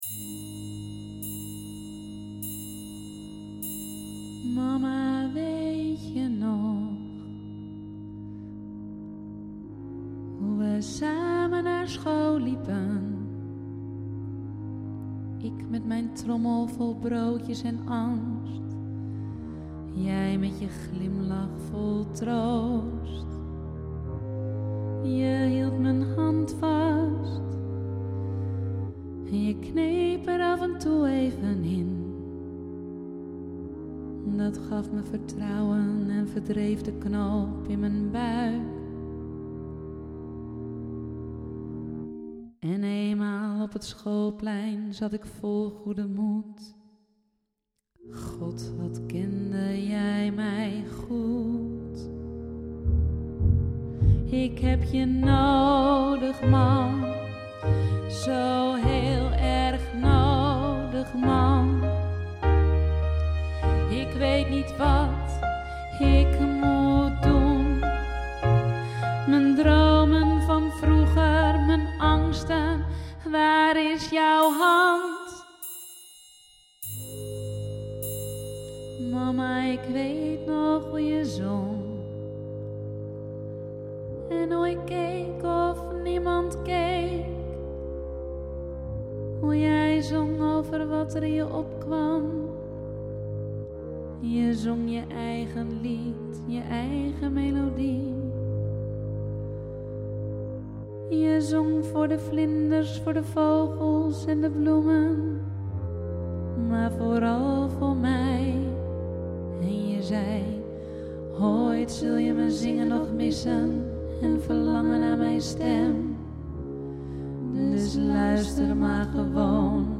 9.-Mama-weet-je-nog-tutti.mp3